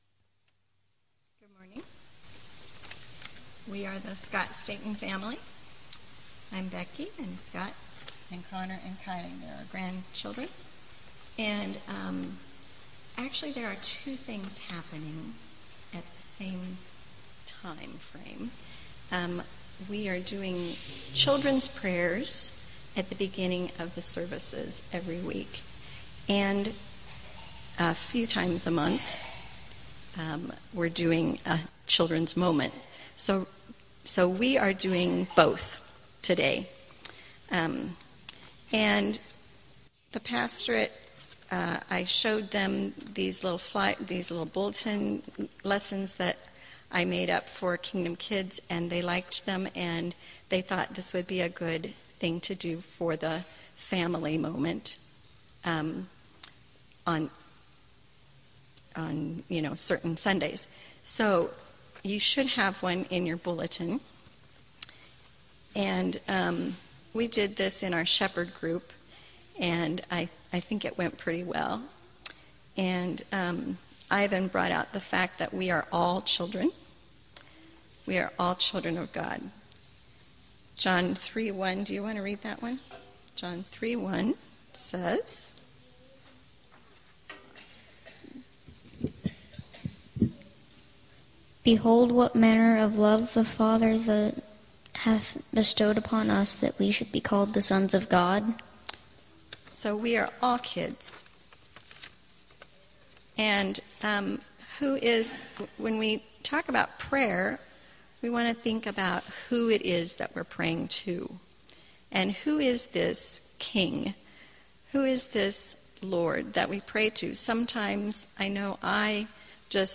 Audio-Full Service